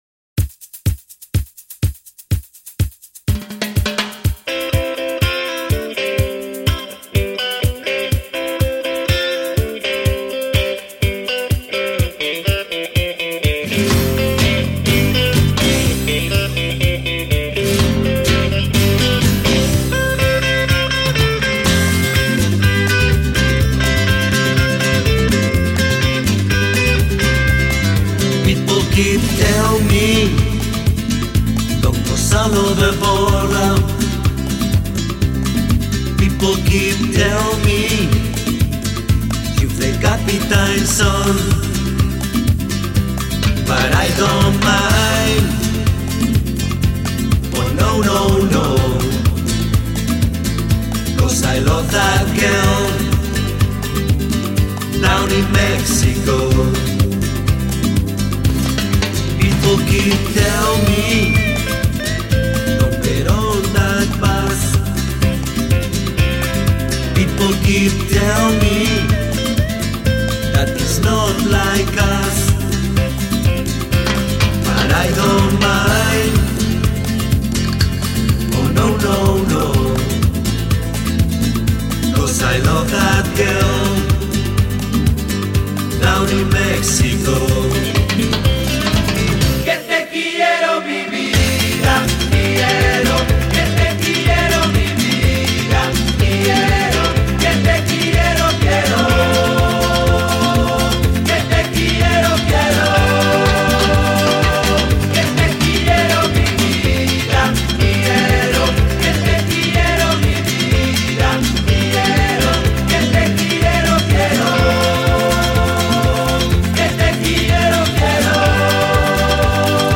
Irresistible flamenco rumba from Spain.